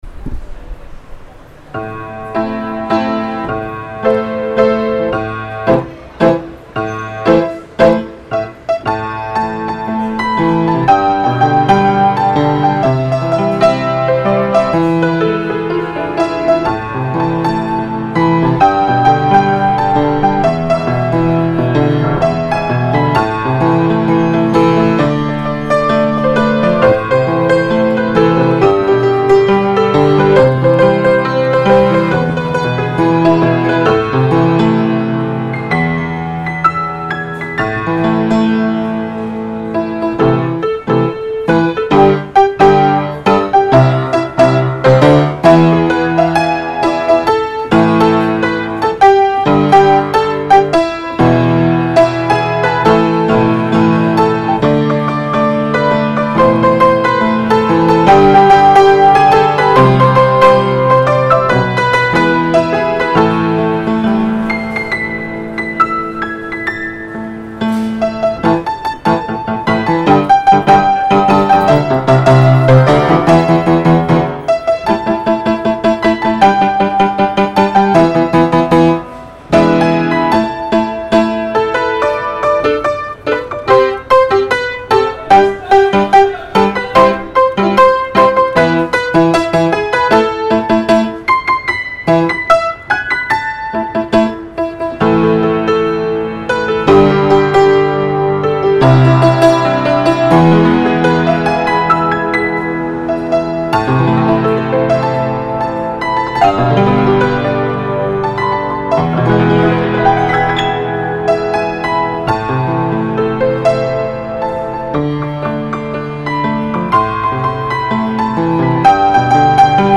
פסנתר